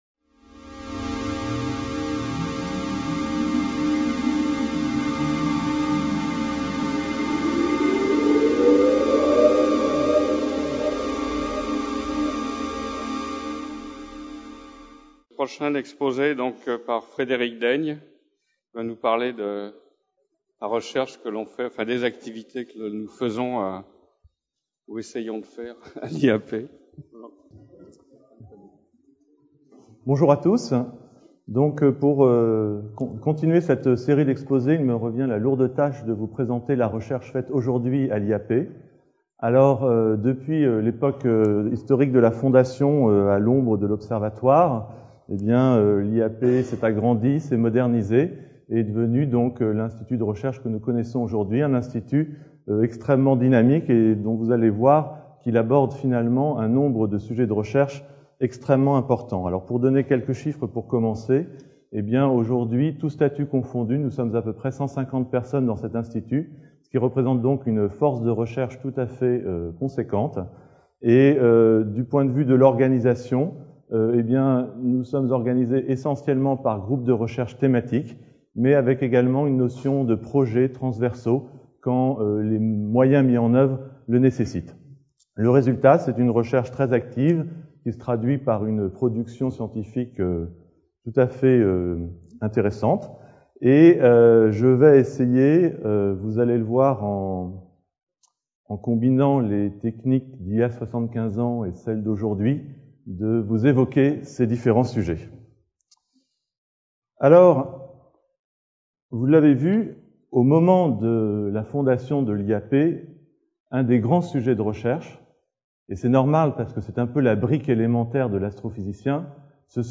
Créé sous le Front populaire, rattaché au CNRS dès sa naissance en octobre 1939, l’Institut d’astrophysique de Paris fête son 75e anniversaire le 11 octobre 2013. Intervention